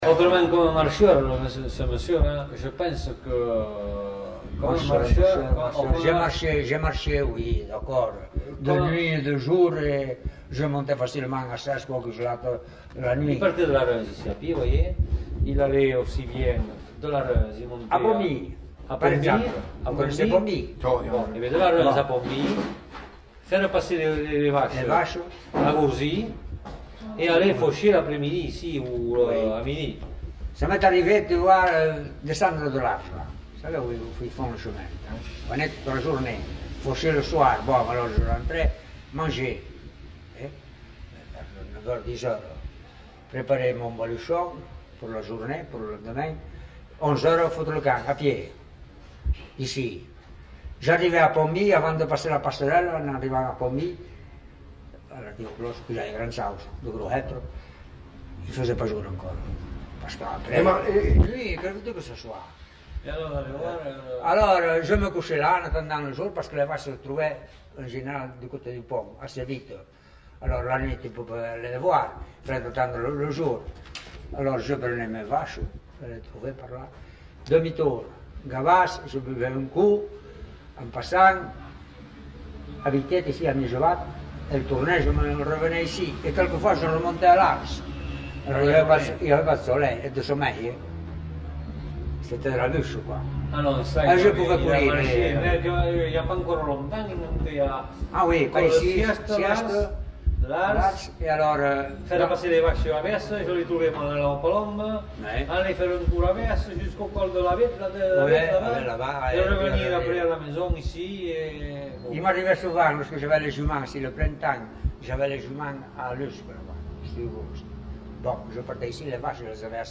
enquêtes sonores